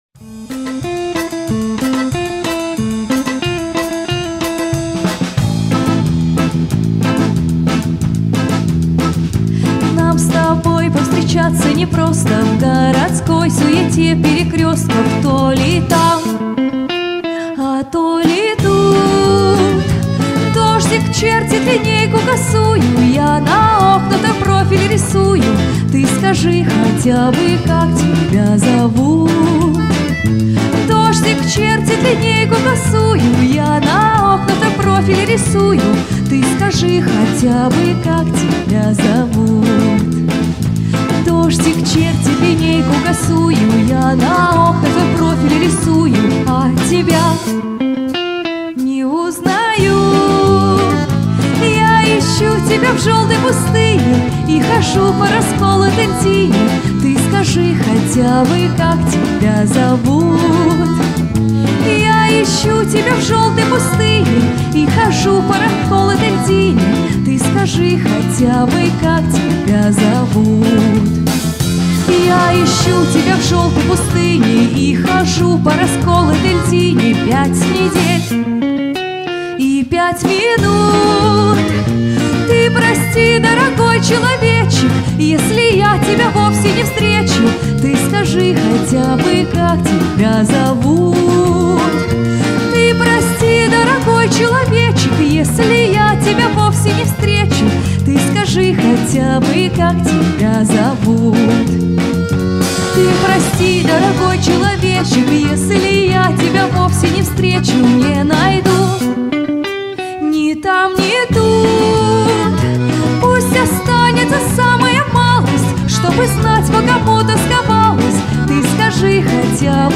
исполняет шлягеры советской эстрады 50-70х. годов.
аккордеон
бас гитара
барабаны